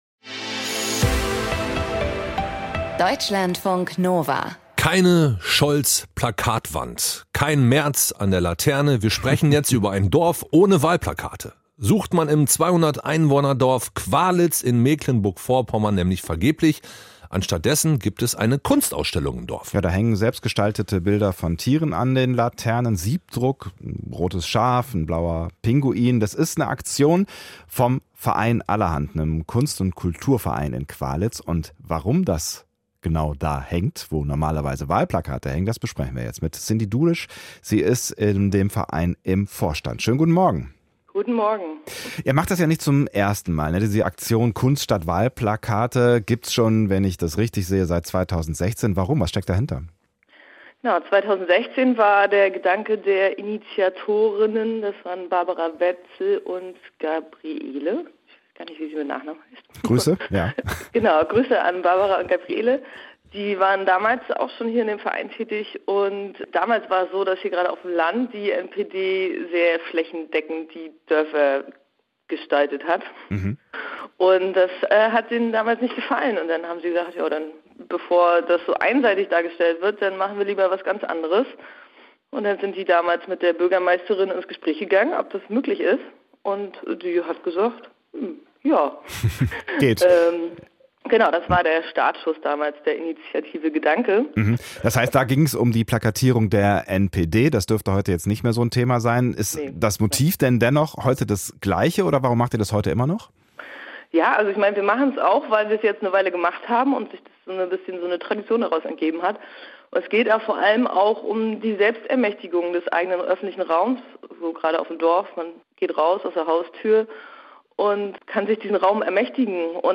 Email Audio herunterladen Der österreichische Kabarettist Josef Hader spricht im Berliner Humboldt Forum über sein Heimatland, die Rolle der Kunst und die Frage, ob die Welt wirklich immer schlechter wird.